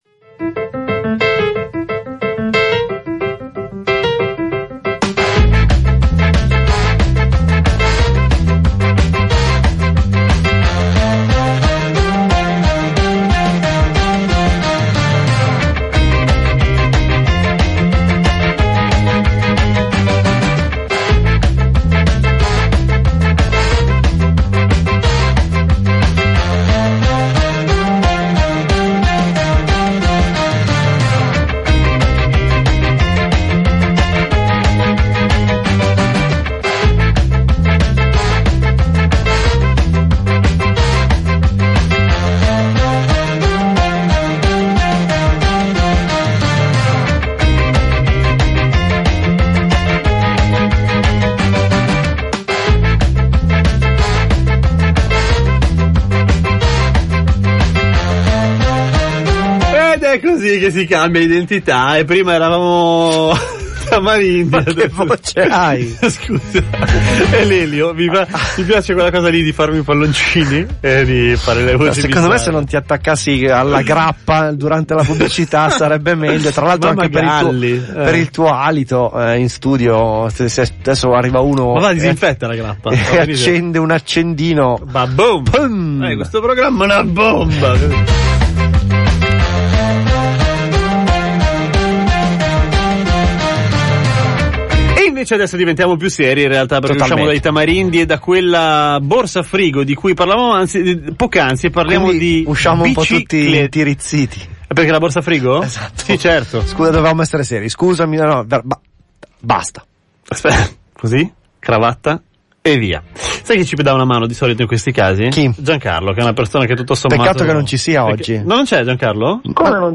Riascolta i podcast della trasmissione L'Agenda Ritrovata andata in onda sulle frequenze di Radio Popolare durante il periodo della ciclostaffetta.